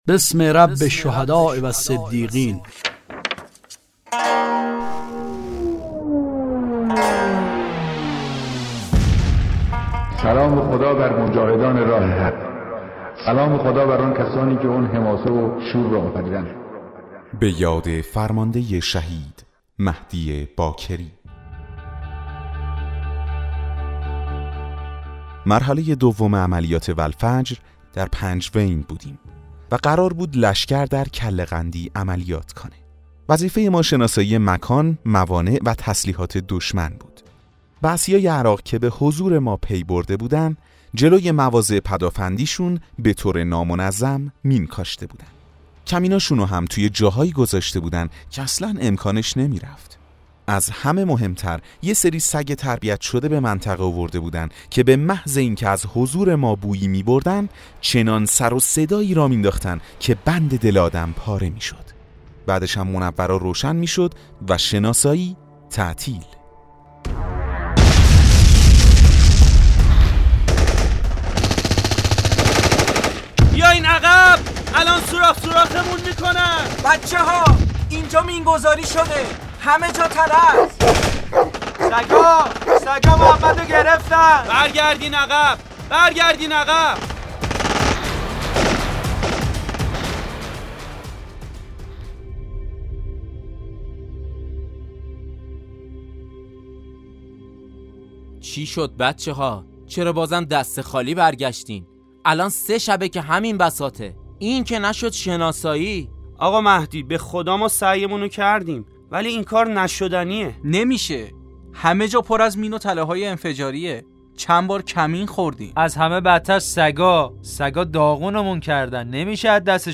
داستان صوتی شهید مهدی باکری